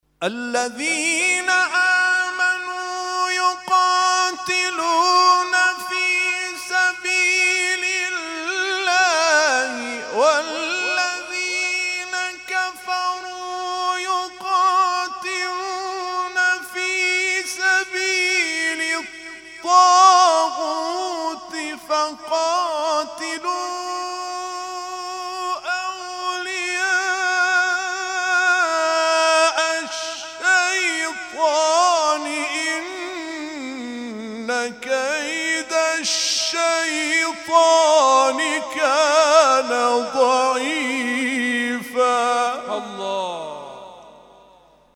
محفل انس با قرآن در آستان عبدالعظیم(ع) + صوت